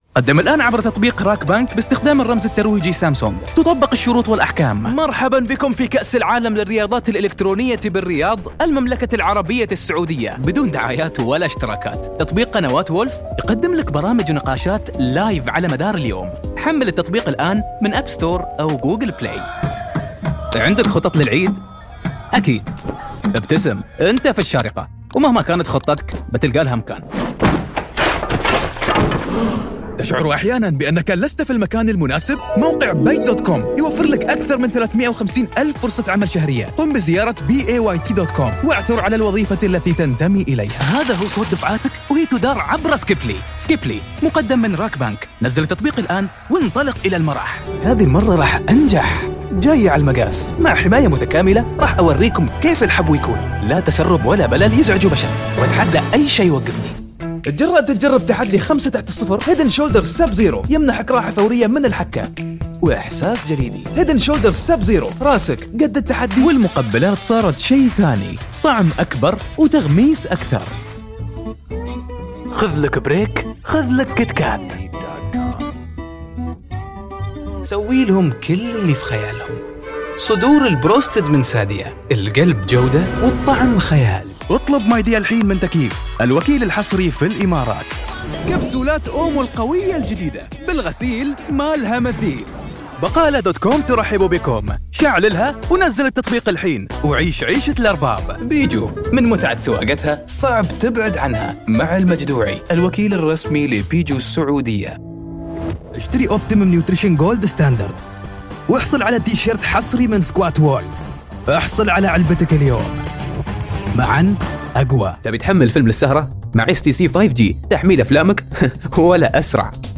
Kommerziell, Warm, Corporate, Tief, Vielseitig
Kommerziell